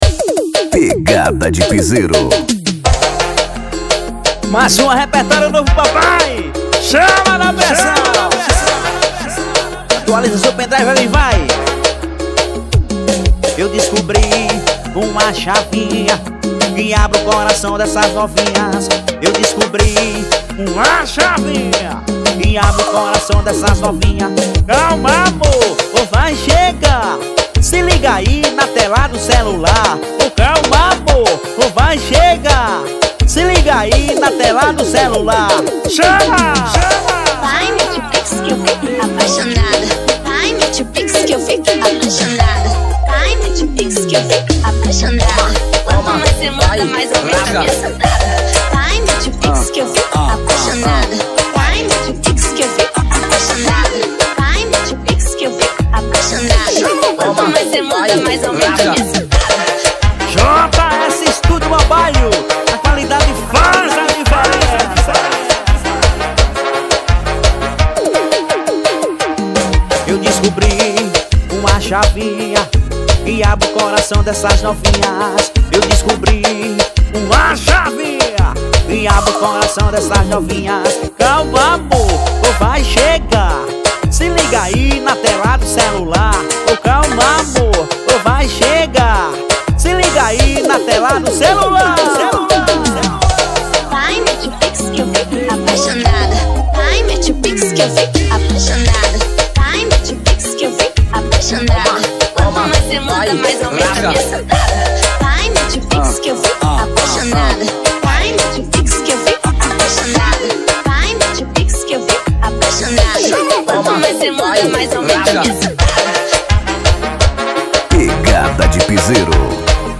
EstiloPiseiro
Cidade/EstadoCaldas Brandão / PB